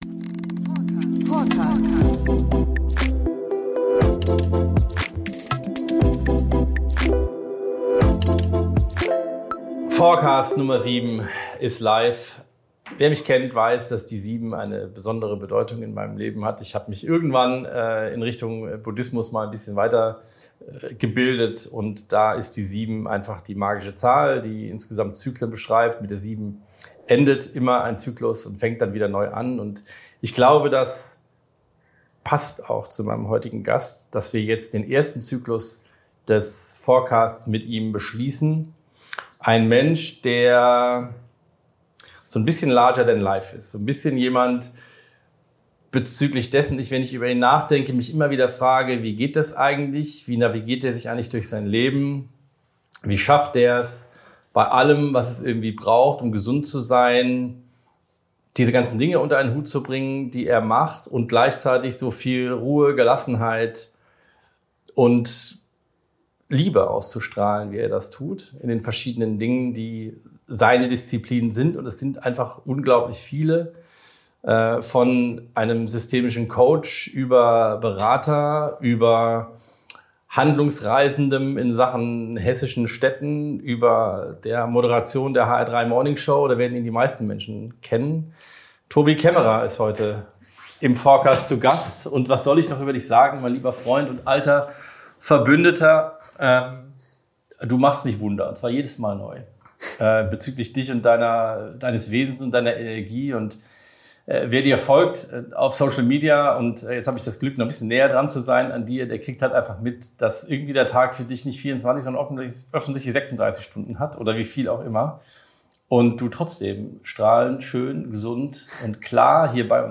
Ein „bassiger“ Wohlklang für die Ohren. Und für Fans hessischer Sprachkultur.